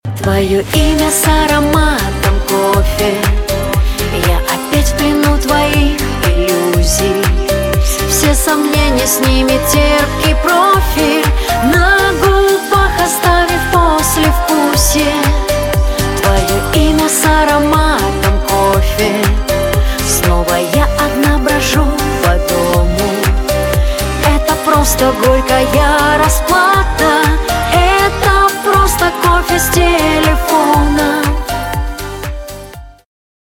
• Качество: 320, Stereo
женский вокал
русский шансон
лиричные